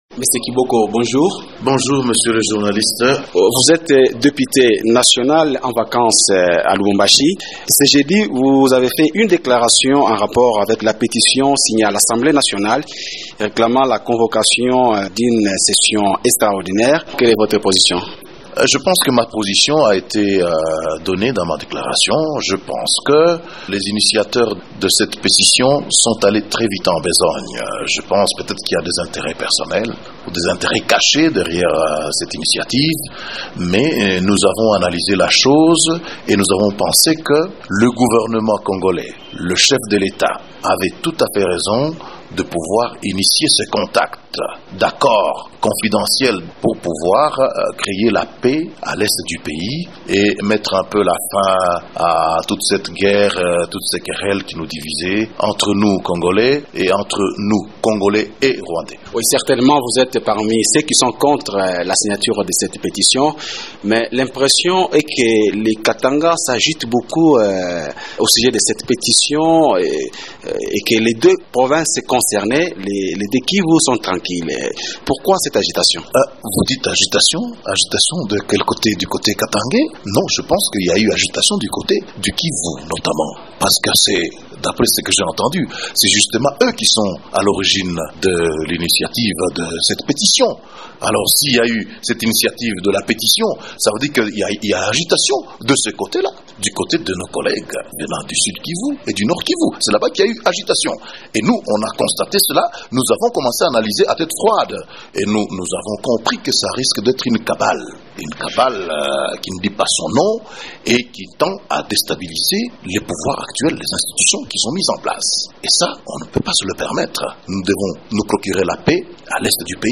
Qu’est-ce qui justifie ces réactions des représentants du Katanga ? réponse avec Vano Kiboko, président du groupe des députés nationaux du Katanga dans cet entretien